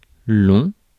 Ääntäminen
IPA: /lɔ̃/